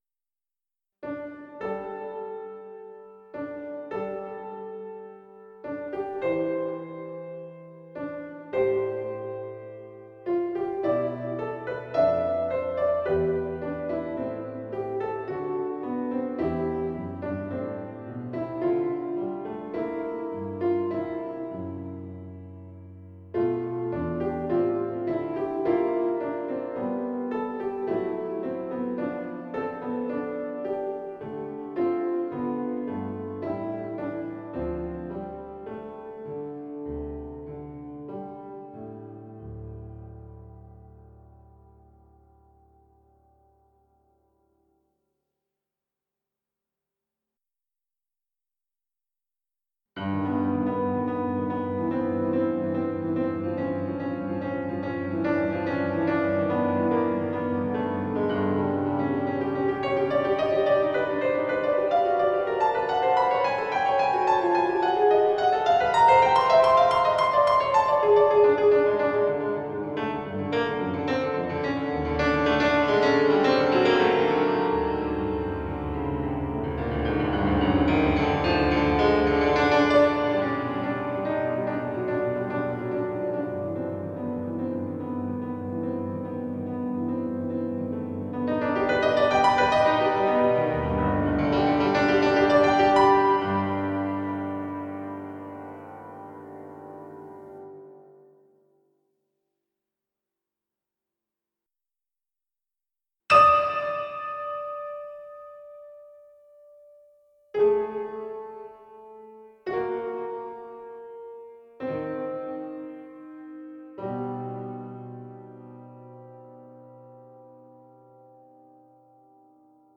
Full Score
Not so here; just a scheme of two half-circles-of-fifths, going alternately up and down, to work through the twelve ( pout ) possible ( shudder ) "keys" ( cringe ).
PreludesPfOp20.mp3